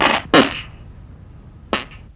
文件夹里的屁 " 屁07
描述：从freesound上下载CC0，切片，重采样到44khZ，16位，单声道，文件中没有大块信息。准备使用！在1个文件夹中有47个屁;）
Tag: 喜剧 放屁 效果 SFX soundfx 声音